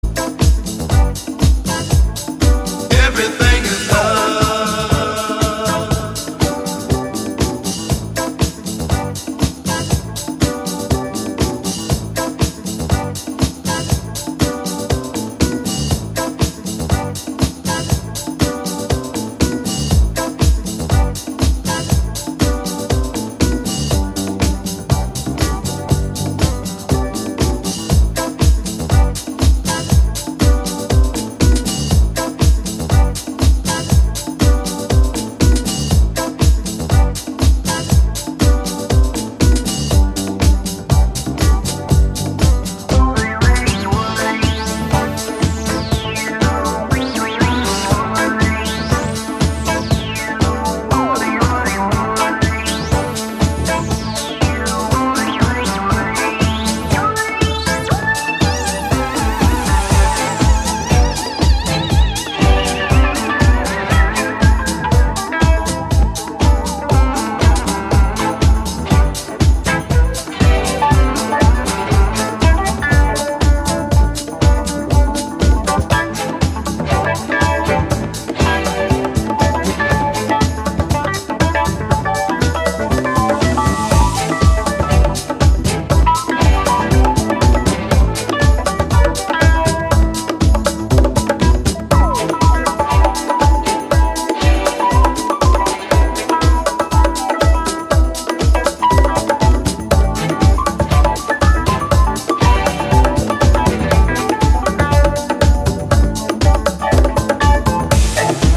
Chicago disco groover